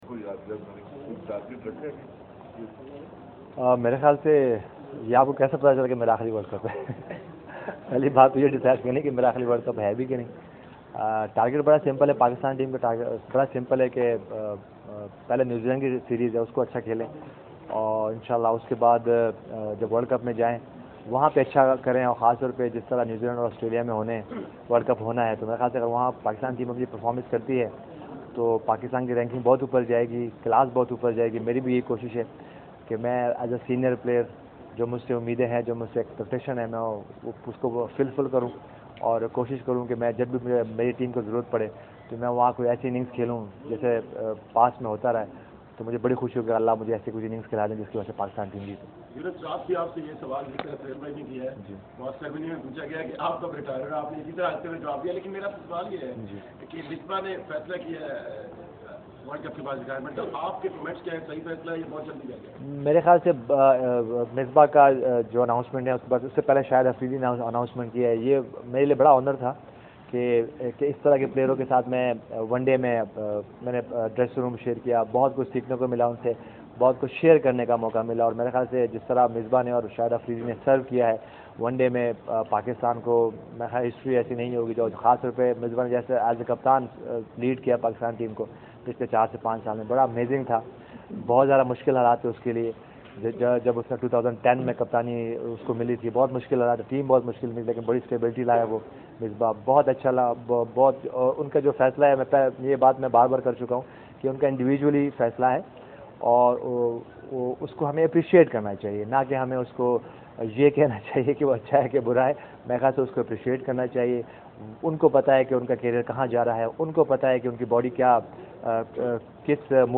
Media Talk: Younis Khan during Pakistan Team practice session at Gaddafi Stadium (Audio)